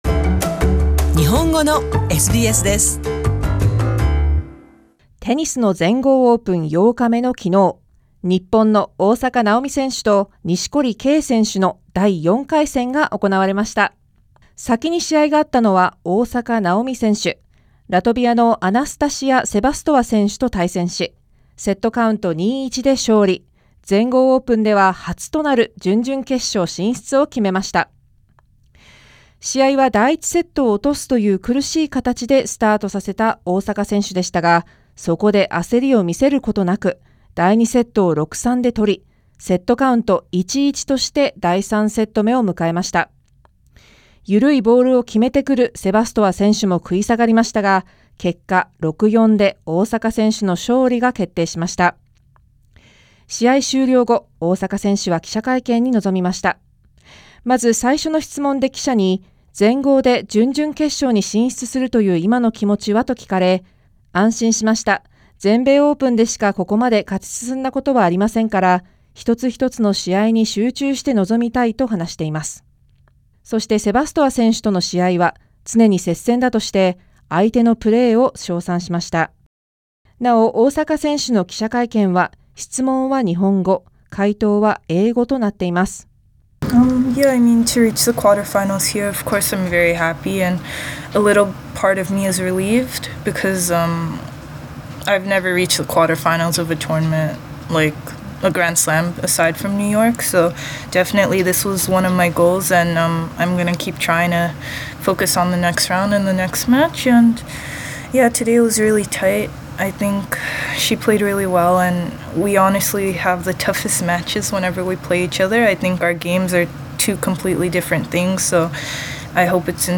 テニスの全豪オープン8日目の21日、日本の大坂なおみ選手と錦織圭選手の第4回戦が行われ、両選手とも接戦を制し、準々決勝進出が決まりました。両選手試合直後の記者会見まとめをお送りします。